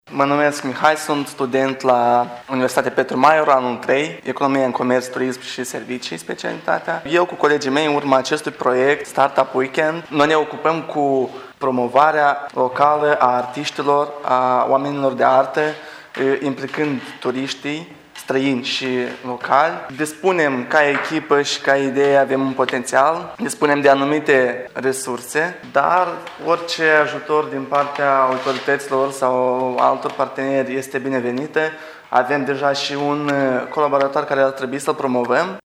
Cei care au câştigat locul I spun că ceea ce şi-au dorit a fost să creeze un concept prin care să promoveze artiştii locali: